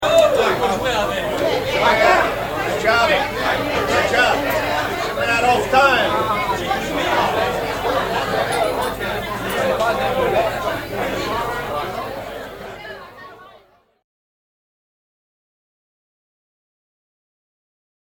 party.mp3